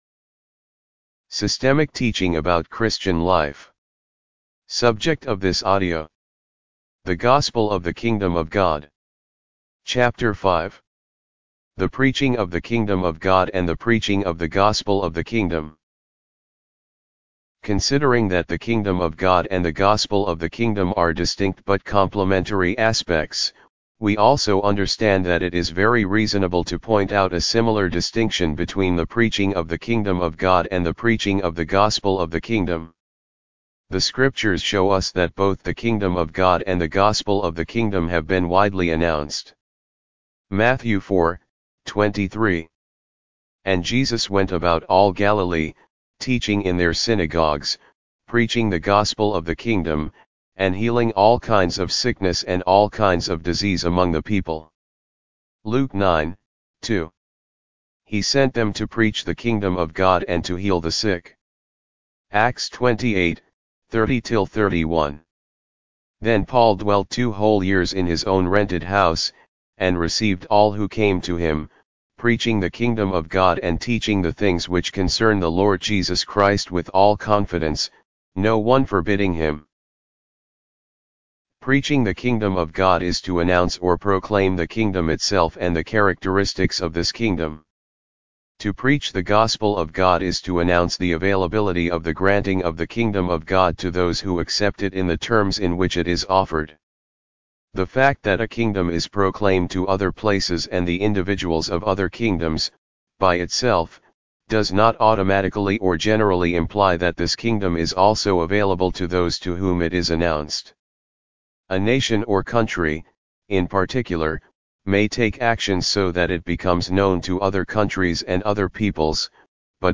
Audibooks - Systemic Teaching about Christian Life Audio Book divided into chapters.